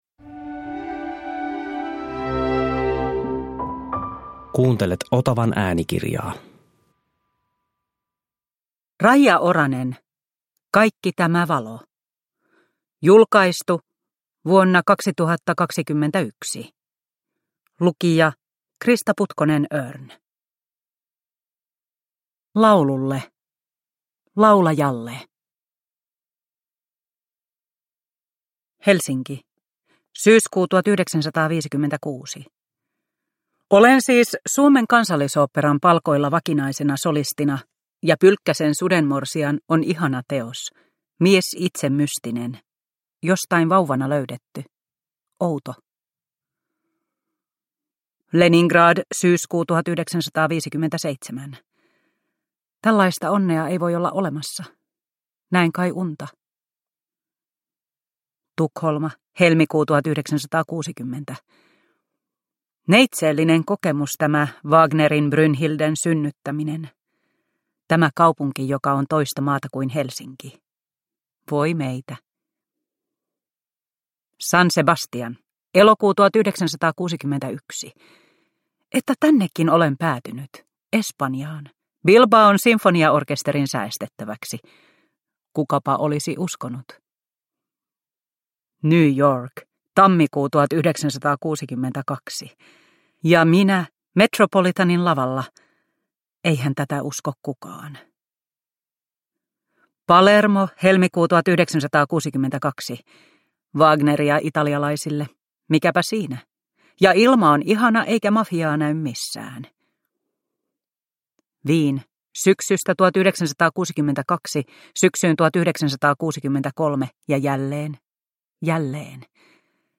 Kaikki tämä valo – Ljudbok – Laddas ner